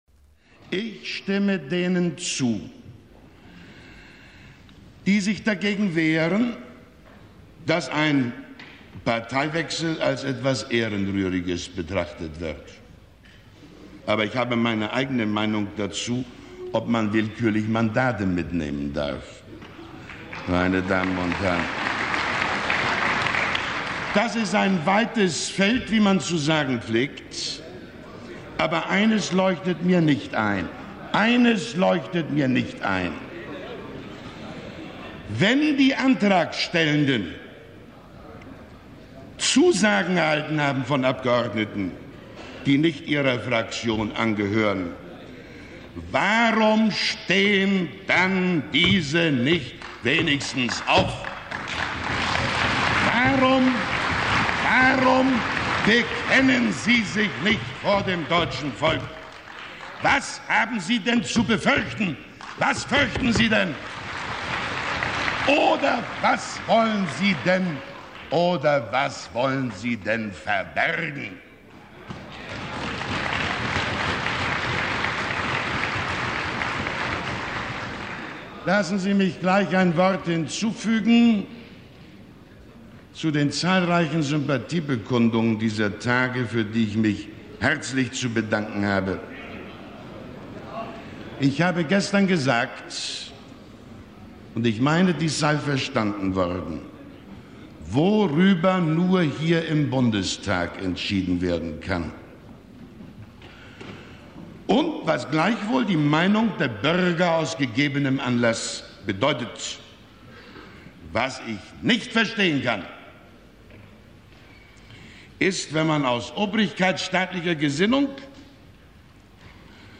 Speech of Willy Brandt against the CDU/CSU’s constructive motion of no confidence, 27 April 1972
Excerpt from the Chancellor’s contribution in the Bundestag debate on the motion to oust him from power
1972_Bundestag.mp3